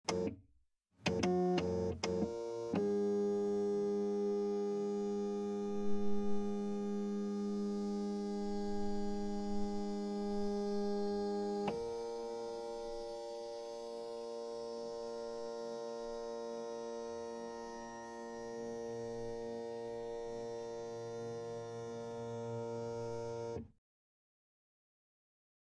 Scanner; Buzz And Clicks.